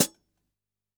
Index of /musicradar/Kit 14 - Acoustic